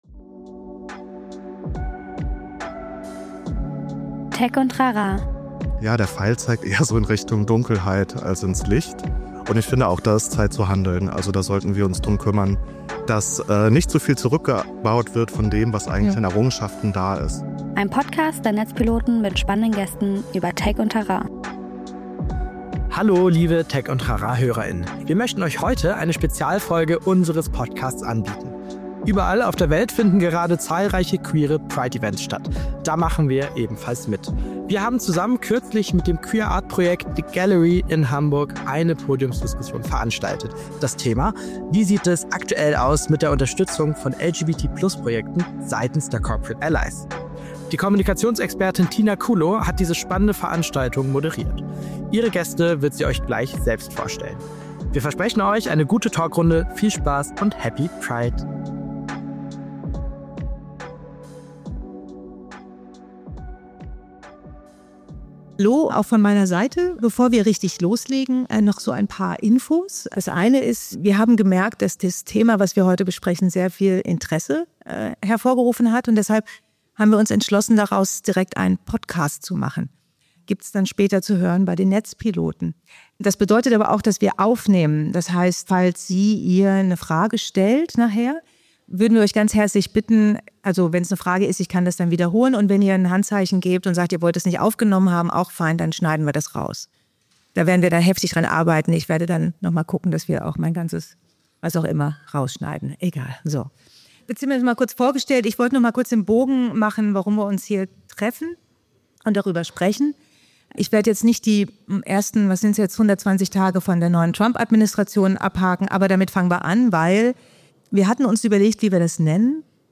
Was bedeutet der Rückzug von Sponsoring, Pride-Engagements und Diversity-Programmen für Unternehmen hierzulande? Und welche Folgen hat das für die Förderung queerer Kultur und Kunstschaffender in der Zukunft? Gemeinsam mit dem queeren Künstlerkollektiv "The ( ) Gallery" haben wir Netzpiloten Mitte Mai ein Live-Podiumsgespräch zum Thema "Queer Allies or not?" veranstaltet.